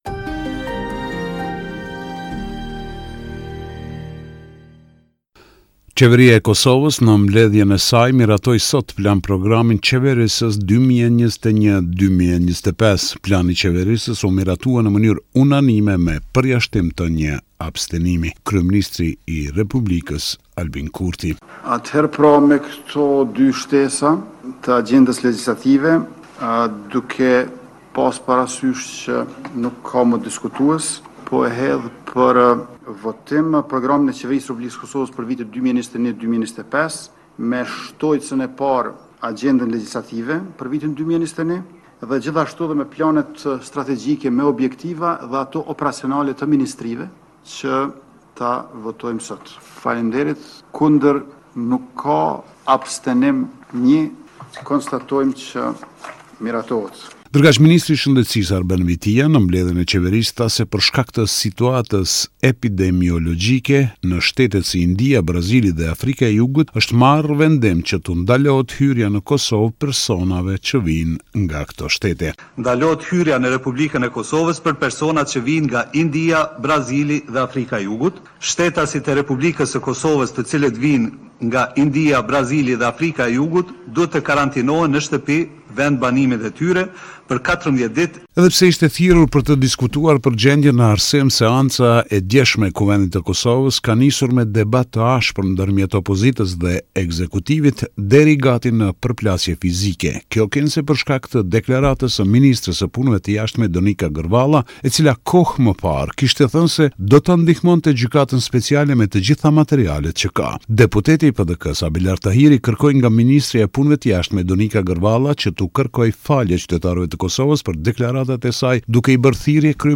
Raporti me te rejat me te fundit nga Kosova.